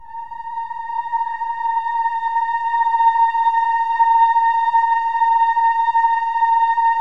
OH-AH  A#5-R.wav